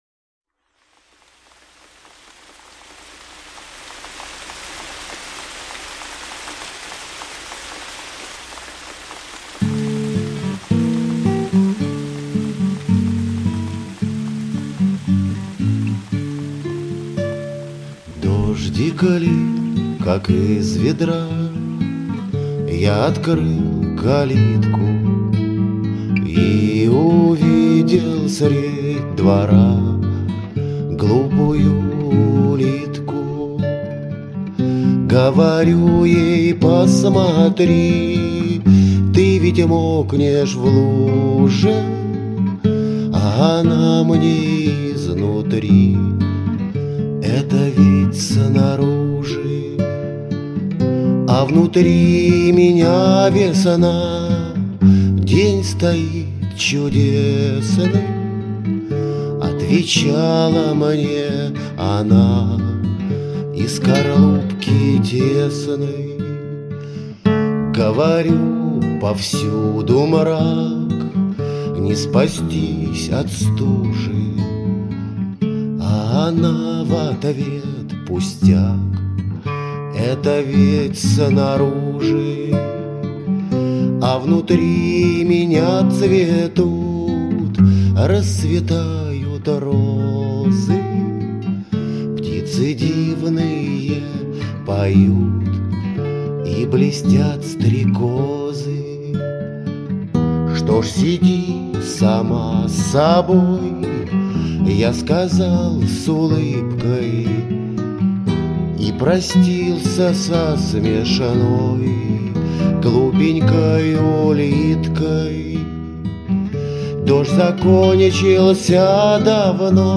• Качество: Хорошее
• Категория: Детские песни
гитара